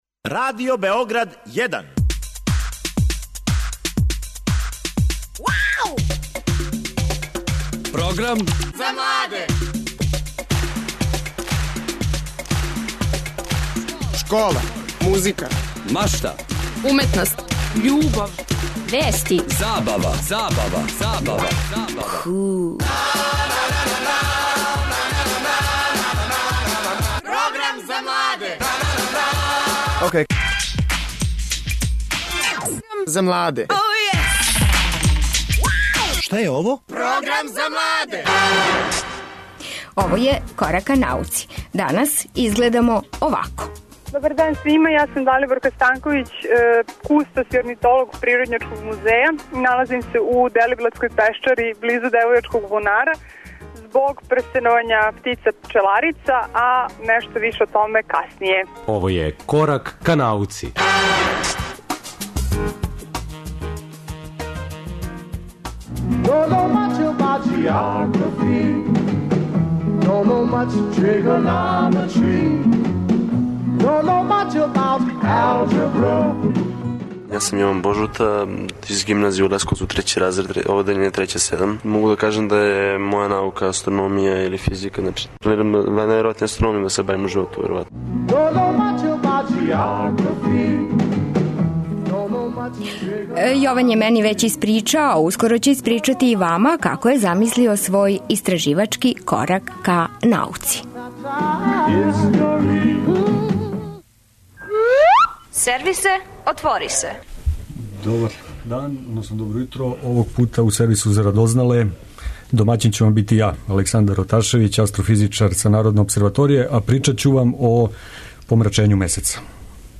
Орнитолози Природњачког музеја су на терену у Делиблатској пешчари. Укључићемо их телефоном да нам пренесу шта се тамо дешава.